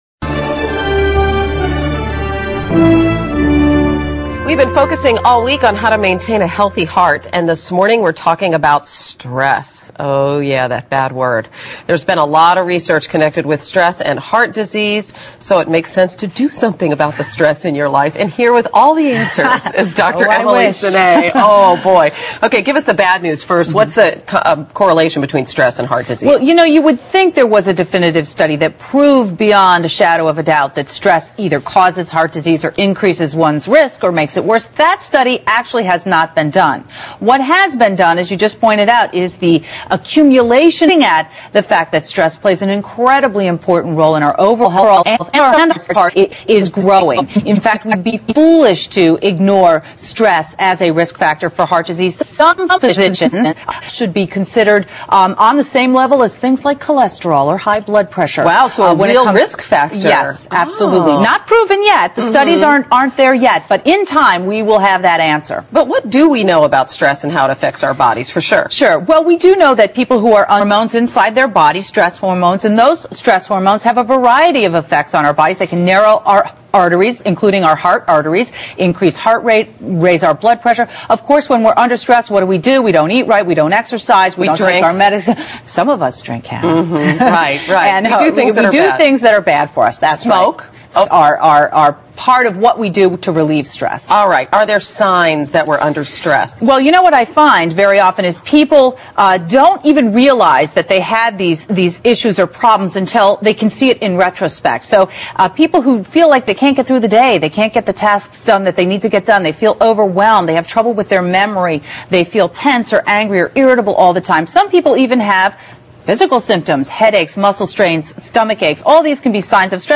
访谈录 Interview 2007-02-18&20, 给心灵做个SPA 听力文件下载—在线英语听力室